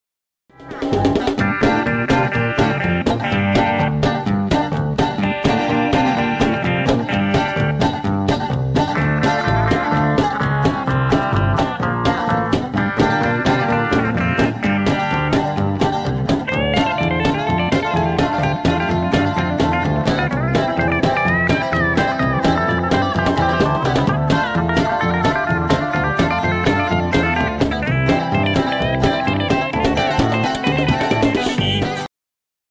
excerpt: guitar solo
country